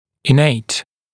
[ɪ’neɪt][и’нэйт]врожденный, природный, присущий, свойственный